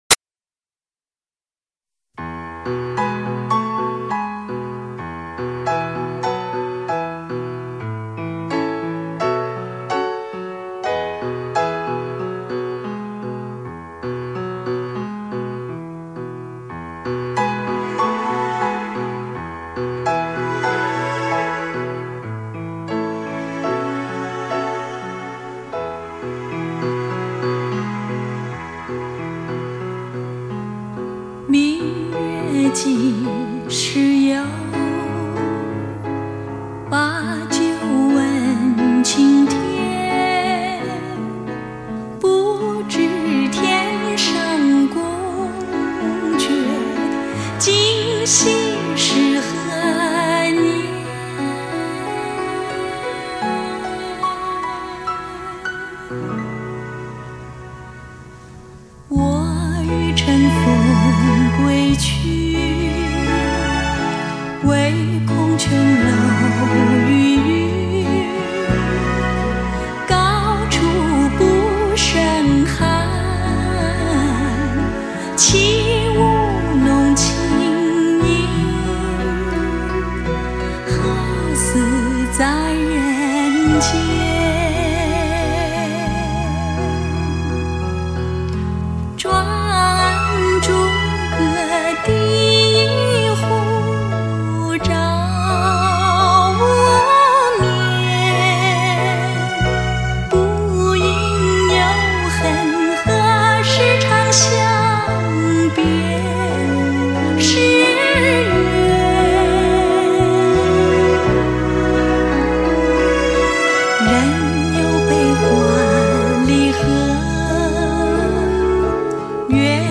HI-FI顶级人声测试大碟